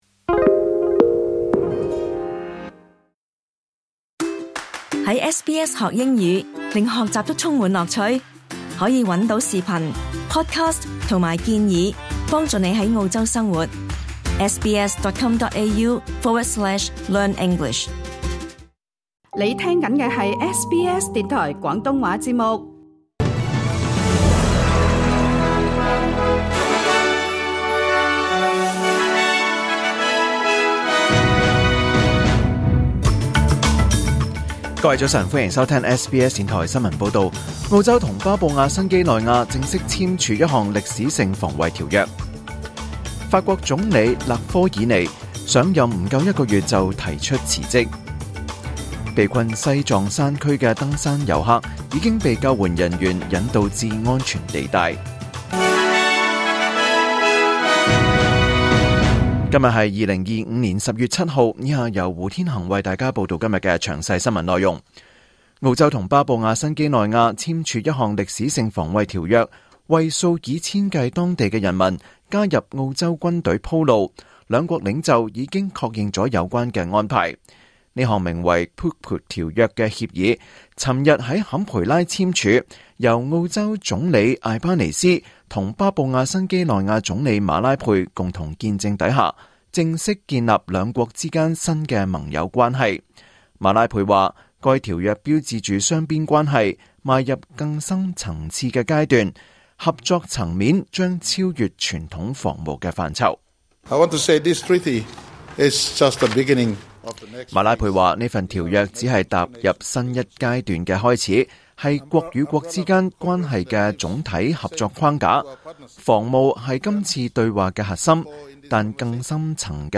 2025年10月7日SBS廣東話節目九點半新聞報道。